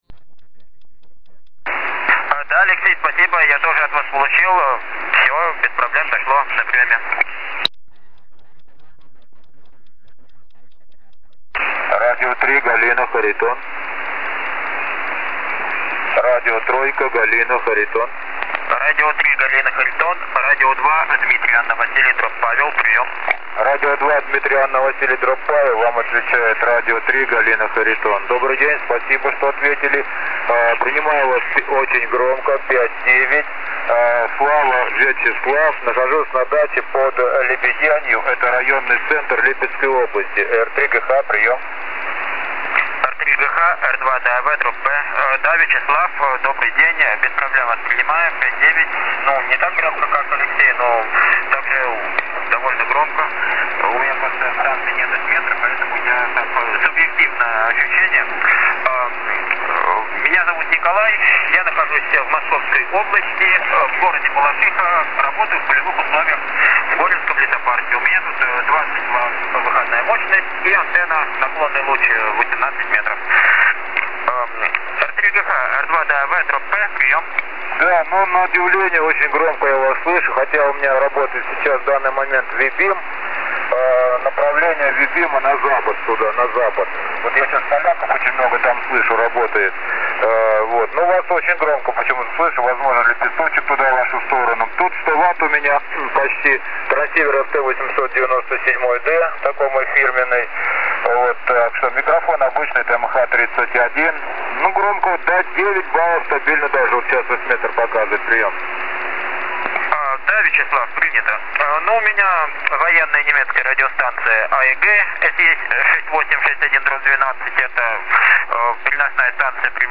Начало » Записи » Записи радиопереговоров - любители и пираты